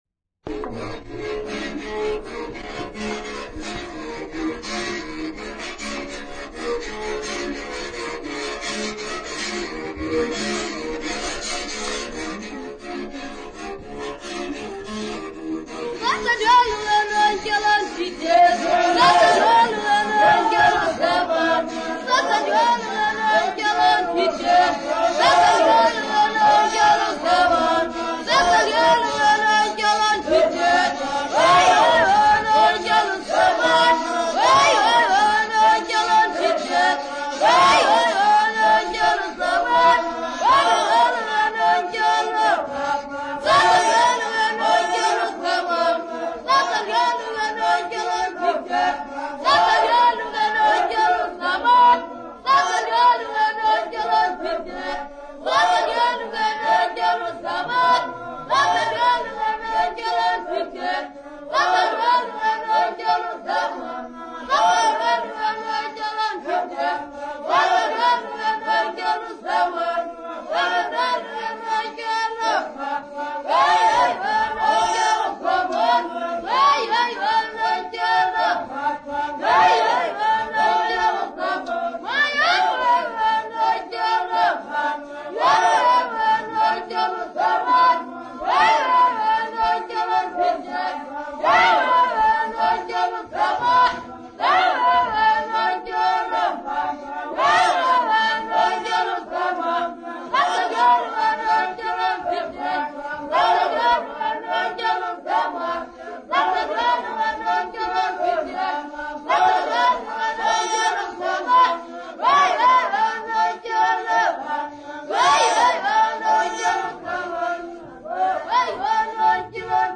Xhosa boys
Folk songs, Xhosa South Africa
Stringed instrument music South Africa
Africa South Africa Lumko, Eastern Cape sa
field recordings
Traditional Xhosa song with inkinge accompaniment.